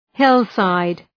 Προφορά
{‘hıl,saıd}